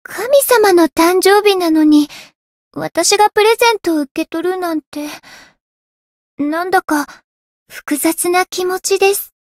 灵魂潮汐-薇姬娜-圣诞节（送礼语音）.ogg